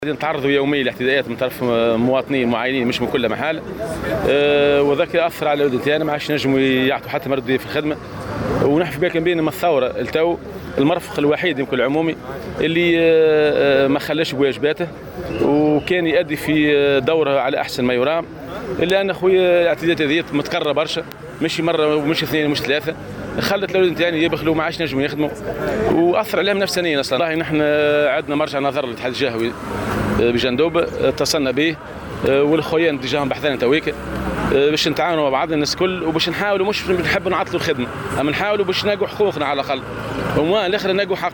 Dans une déclaration accordée au correspondant de Jawhara FM dans la région, l'un des protestataires a appelé les autorités compétentes à agir afin de mettre un terme aux violences perpétrées à l'encontre des agents de la STEG et à appliquer la loi contre toute personne qui empêche les agents de la STEG à travailler.